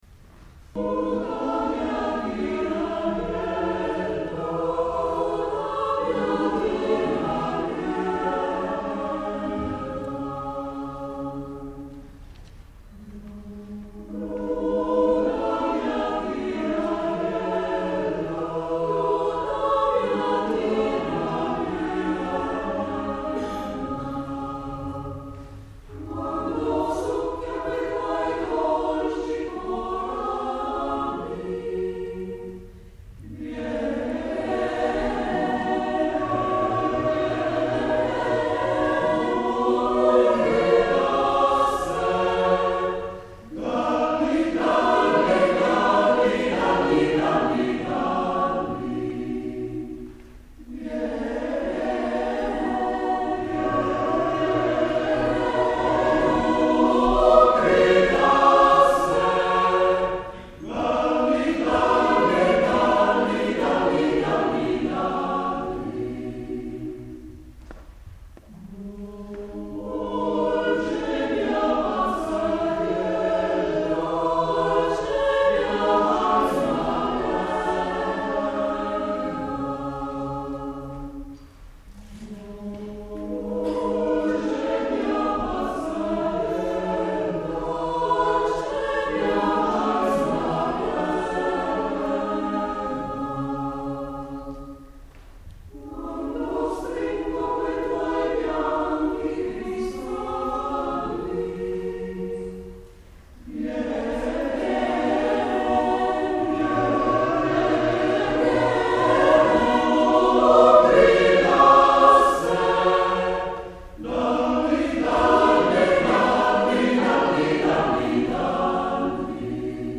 Sala Maestra ~ Palazzo Chigi ~ Ariccia
Coro in Maschera